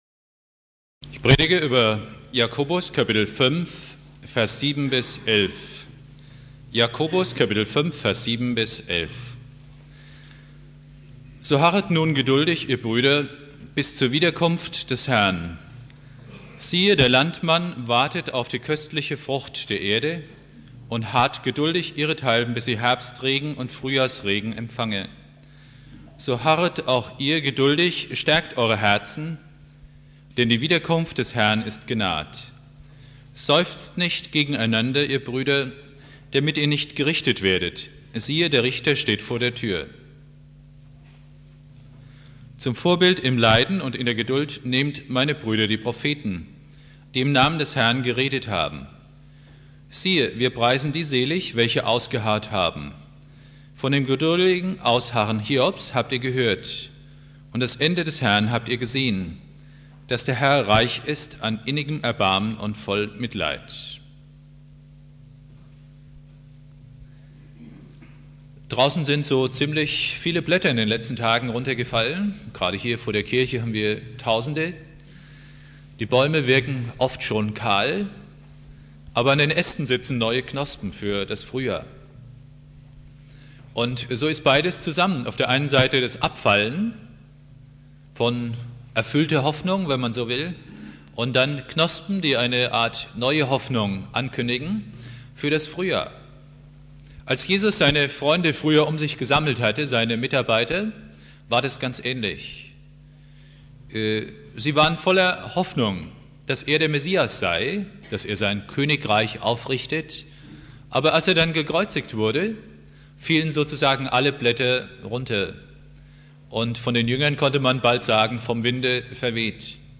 Predigt
2.Advent